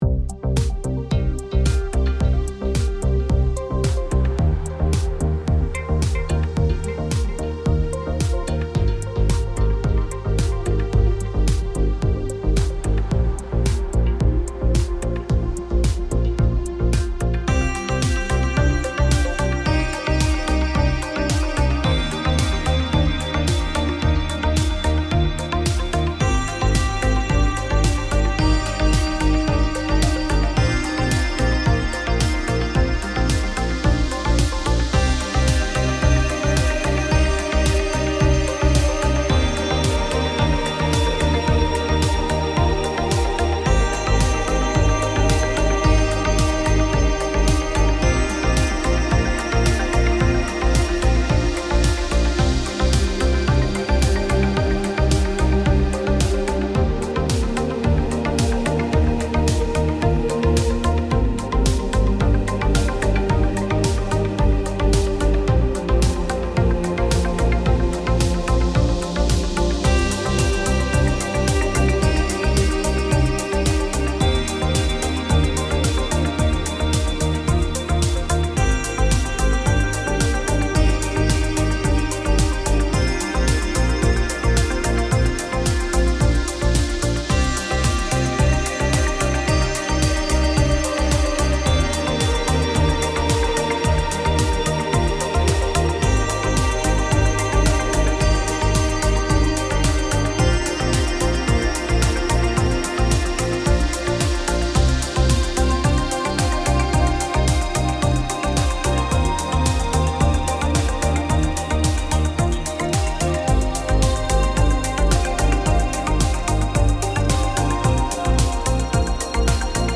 Kick it up with some grooves, with the added 48 drum and percussion hits too. Amethyst is jam packed with leads, basses and keys.